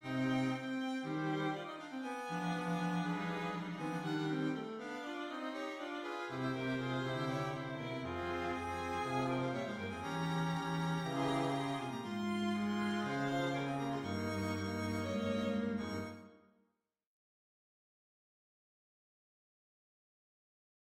C’est à la mesure 73 que se trouvent superposés de manière symétrique le sujet et son inverse.
Cette superposition de S et son inverse va être contrepointée par les basses et altos sur le motif B du CS. Les deux motifs sont de nouveau superposés en fa mes 77 aux basses et altos en contrepoint avec les deux pupitres de violons sur un autre élément issu du CS.